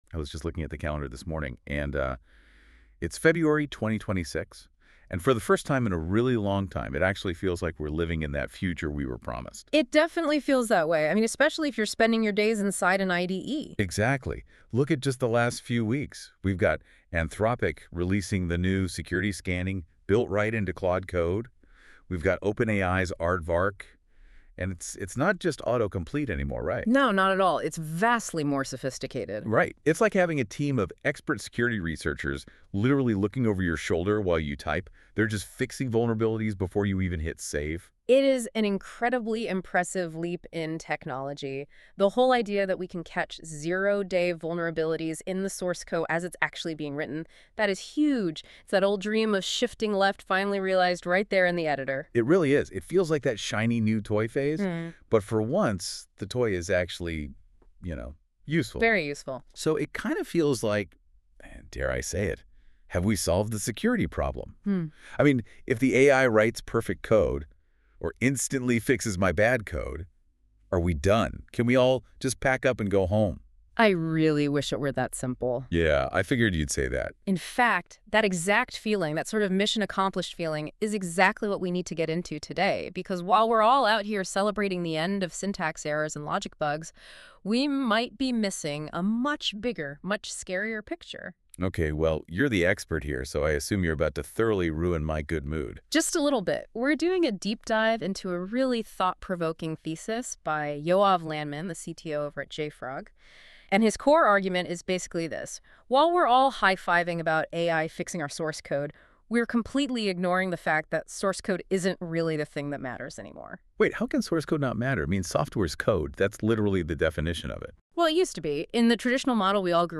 Listen to a NotebookLM podcast version of the blog: